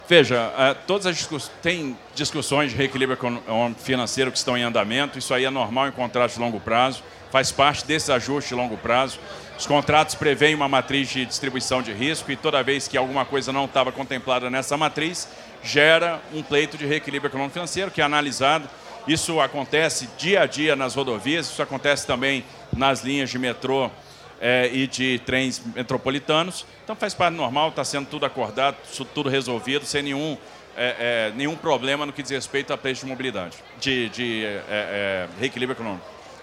ENTREVISTA: Tarcísio promete início das obras da linha 4 em dezembro, operação até Taboão da Serra em 2028 e diz que pedido de reequilíbrio pela ViaQuatro é “normal”